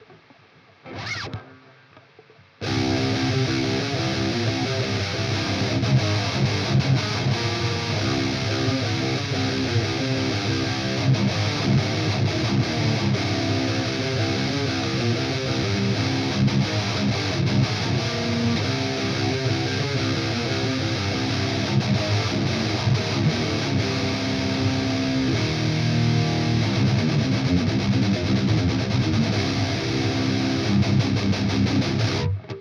here's a little shootout between the OD808, OD820 and an 808 modded TS9
it really doesn't matter all that much, I can hardly hear a difference
The TS9-808 clip and the maxon 808 clip sounds the same to me, the 820 have a little more lowend rumble..
to me the 808 sounds a little bit smoother and thicker but less open than the 9-808.
TS9-808.wav